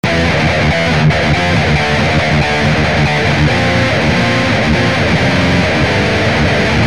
描述：猛烈的快速摇滚金属融合和弦结构
Tag: 70 bpm Heavy Metal Loops Guitar Electric Loops 1.15 MB wav Key : Unknown